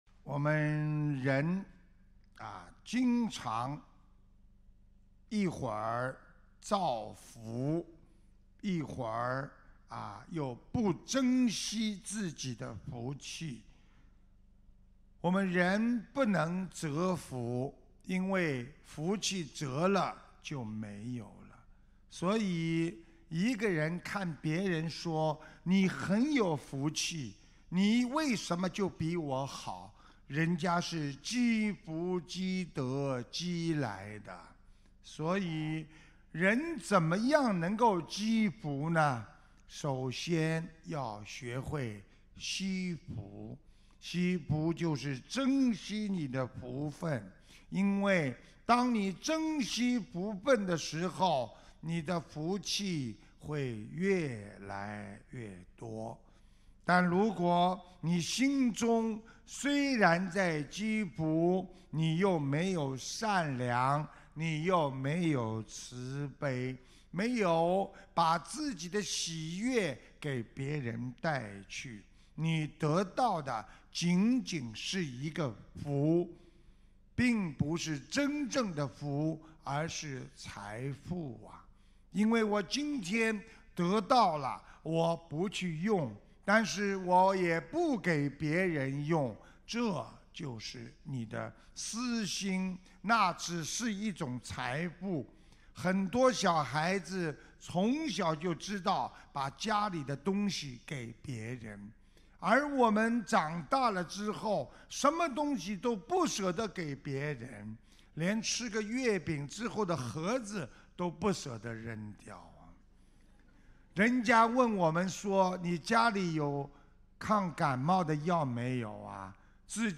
澳大利亚布里斯班